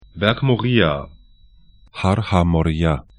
Aussprache
Moria, Berg bɛrk mo'ri:a Har HaMorìyya har ha mɔri'ja he Berg / mountain